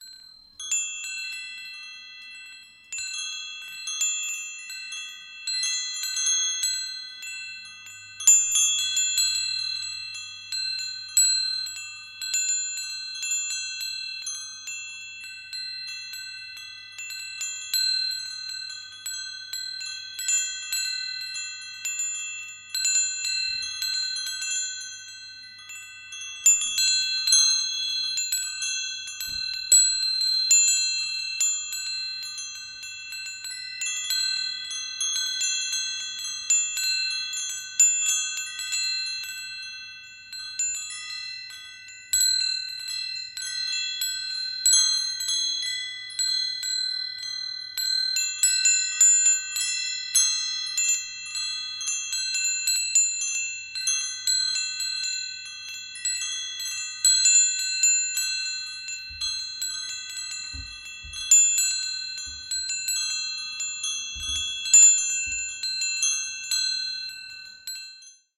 Tiếng Leng Keng